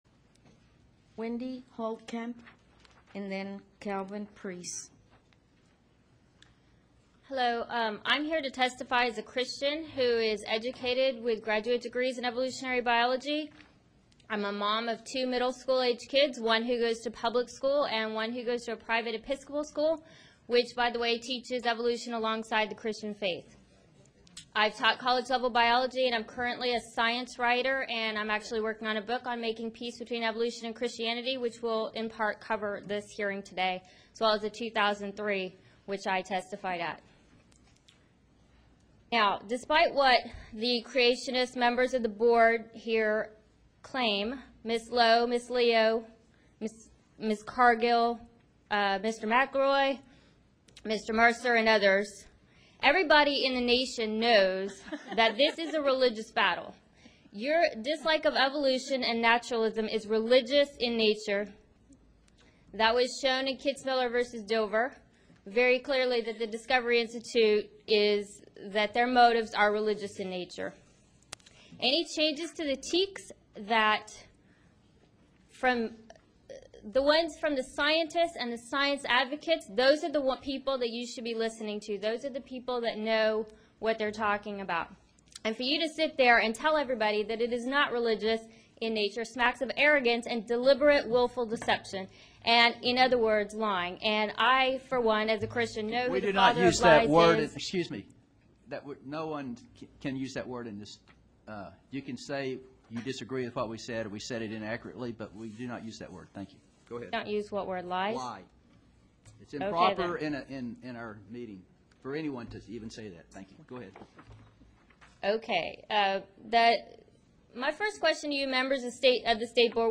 The laughter and talking by the Board was loud enough to be picked up on the live feed that was streamed from the TEA website because someone emailed me commenting on it.
MP3 of my testimony, where you can hear them laughing when I say their names, and McLeroy interrupting me.